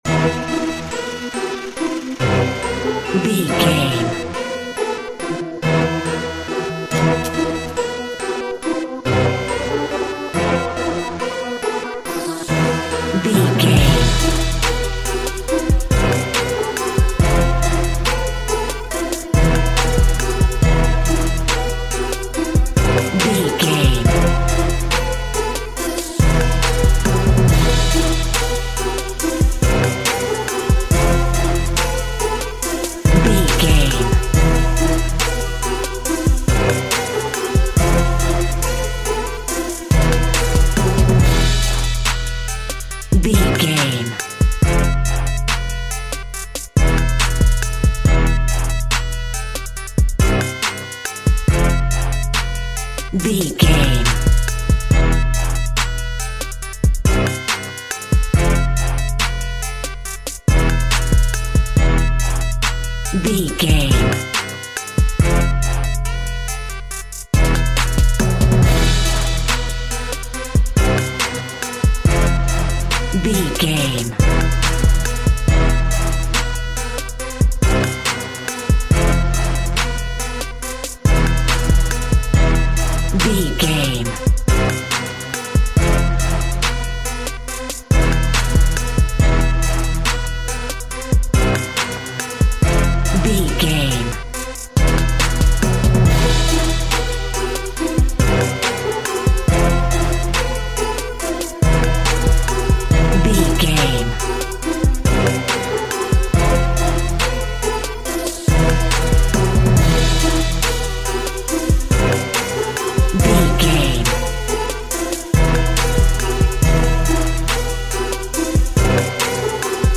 Aeolian/Minor
hip hop
instrumentals
chilled
laid back
groove
hip hop drums
hip hop synths
piano
hip hop pads